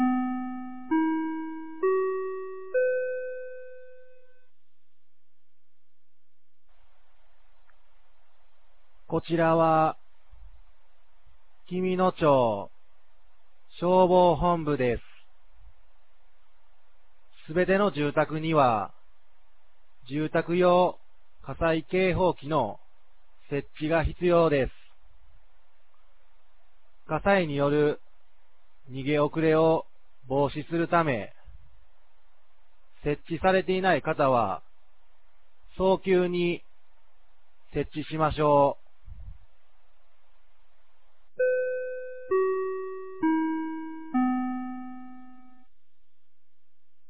2025年08月09日 16時00分に、紀美野町より全地区へ放送がありました。